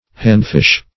\hand"fish`\ (h[a^]nd"f[i^]sh`)
handfish.mp3